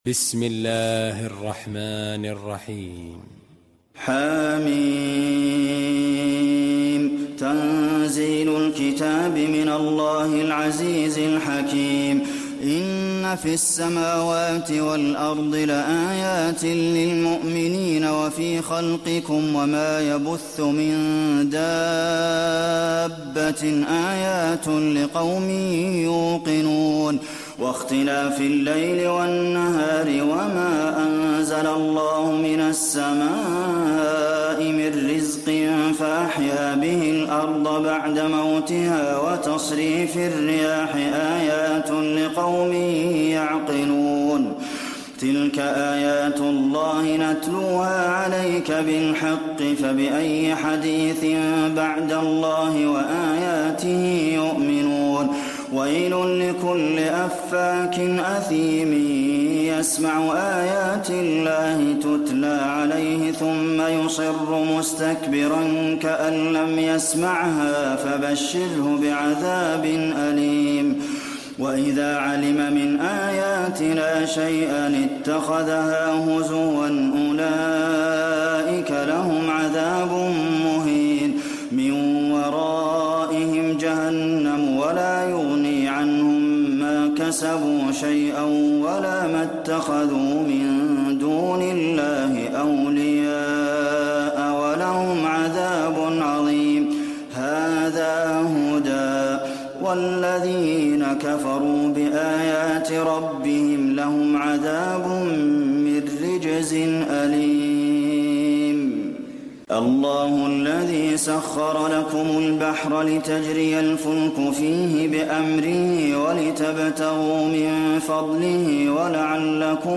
المكان: المسجد النبوي الجاثية